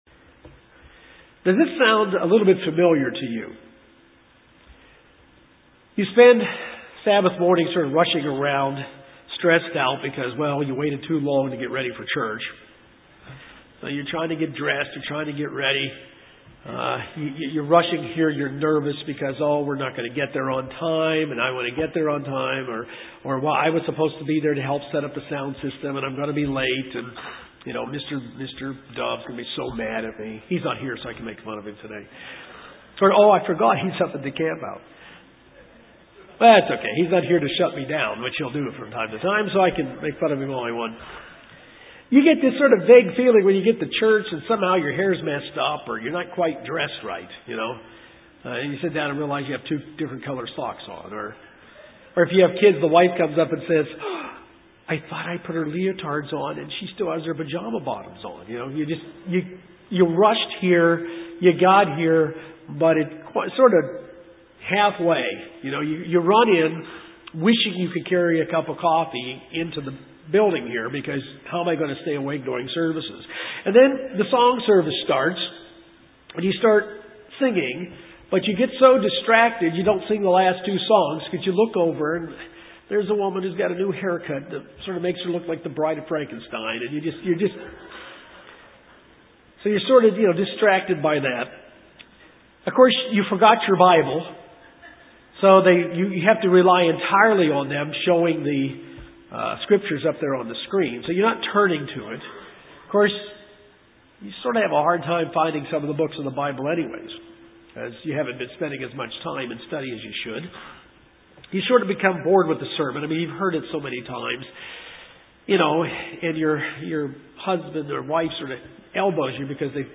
This sermon will answer these questions.